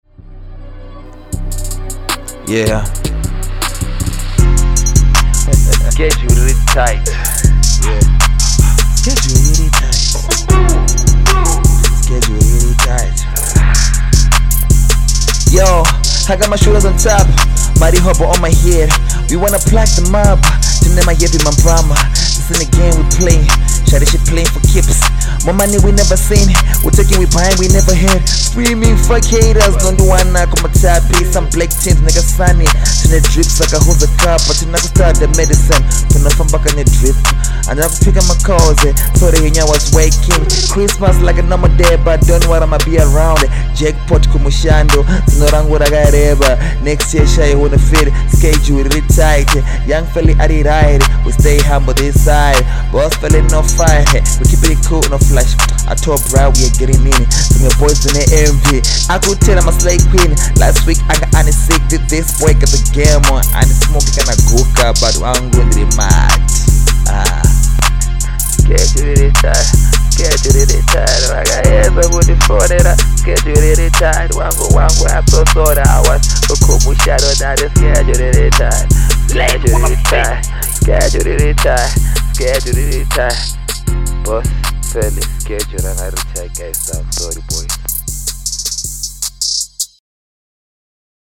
HipHop
Afrobeat. Rap Hiphop New RNB trending
• Genre: HipHop